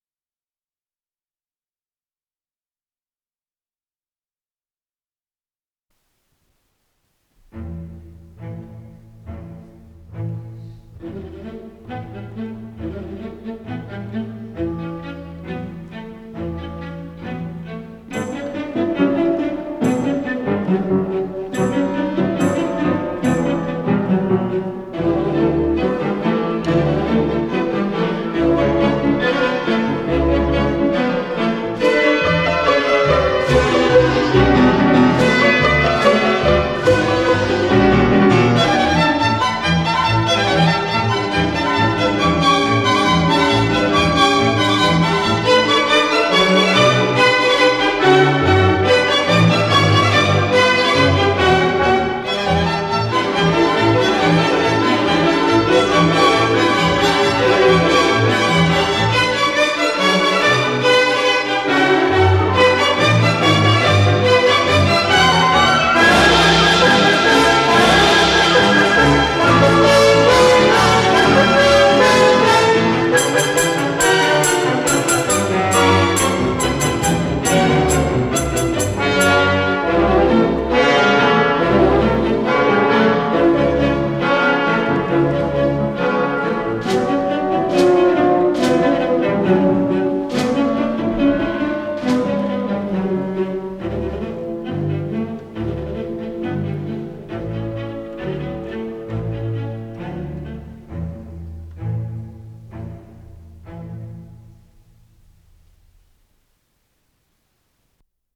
Виво